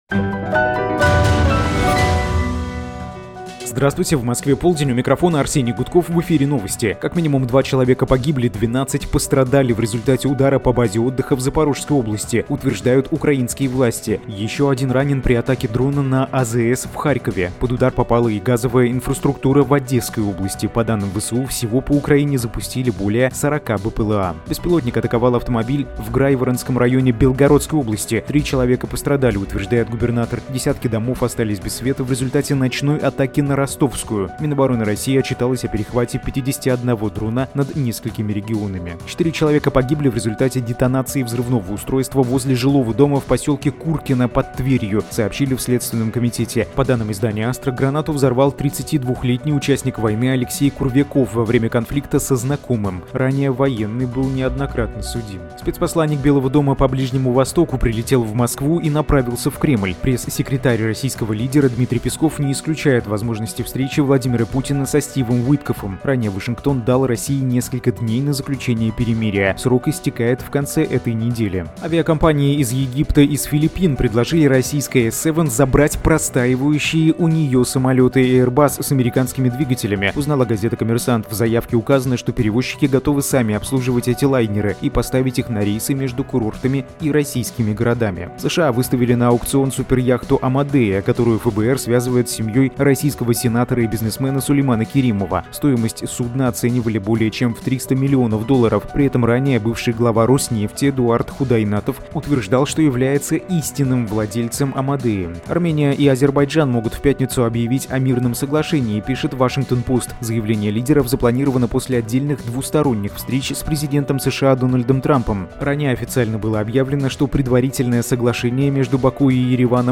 Слушайте свежий выпуск новостей «Эха»
Новости 12:00